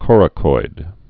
(kôrə-koid, kŏr-)